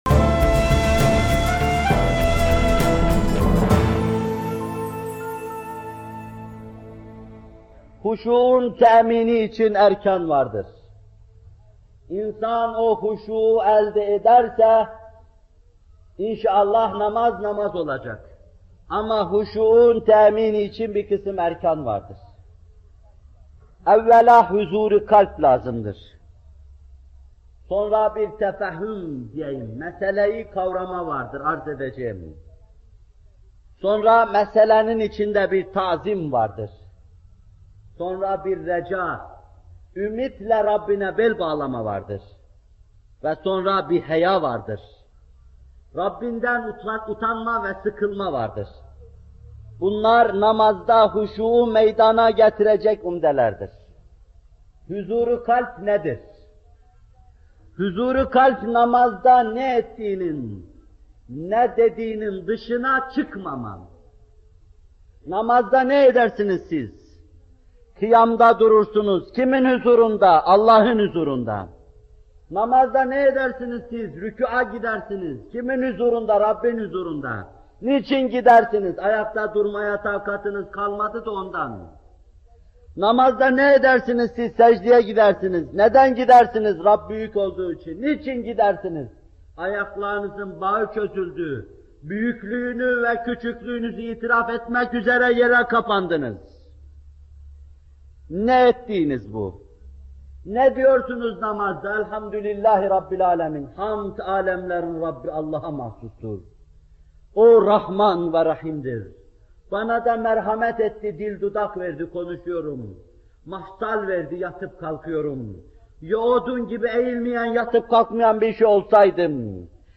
Bu bölüm Muhterem Fethullah Gülen Hocaefendi’nin 15 Eylül 1978 tarihinde Bornova/İZMİR’de vermiş olduğu “Namaz Vaazları 5” isimli vaazından alınmıştır.